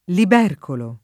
[ lib $ rkolo ]